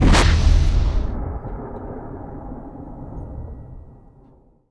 Sci-Fi Effects
engine_warp_003.wav